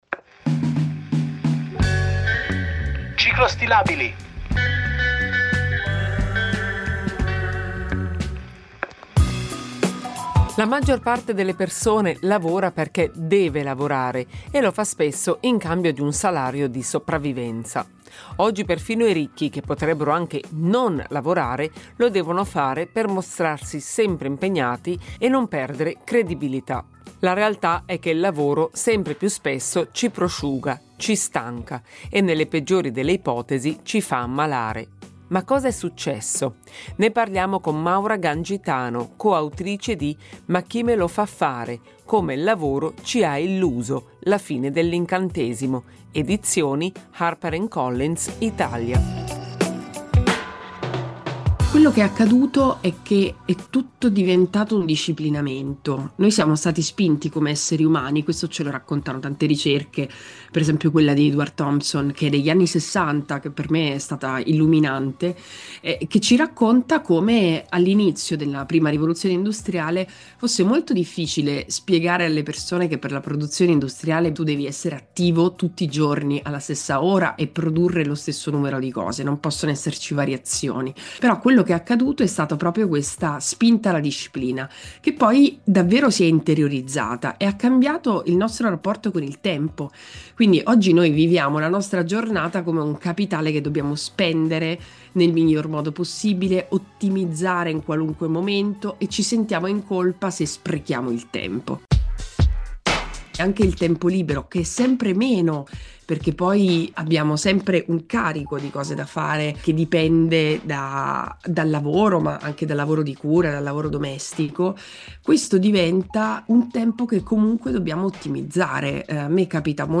Un dialogo